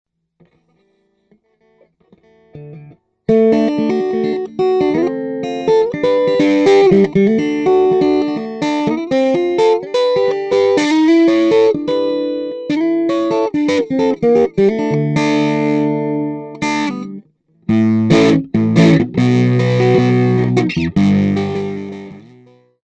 I used my PC headset mike direct into a Toshiba laptop.
Cuts 1-3 are pure, raw cuts, and are a bit misleading.
Light touch start to a bit of grind, no reverb
light2rough.mp3